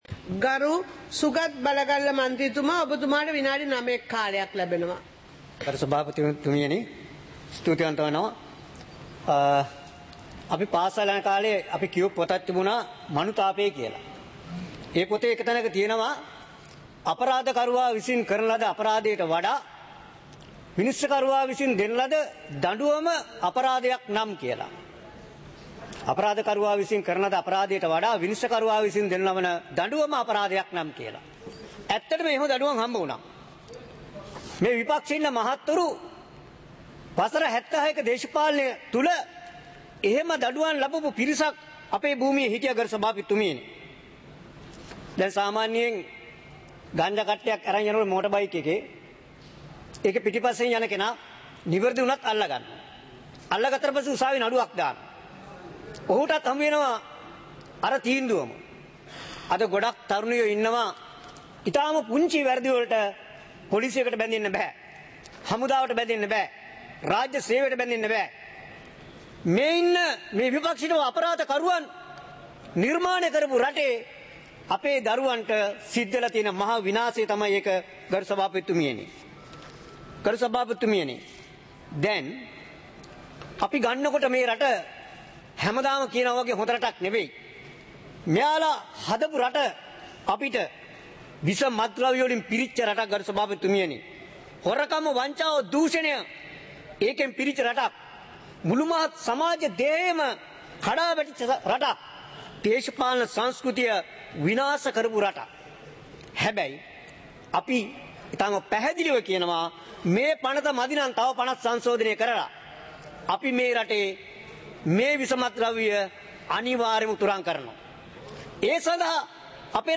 சபை நடவடிக்கைமுறை (2026-02-19)
நேரலை - பதிவுருத்தப்பட்ட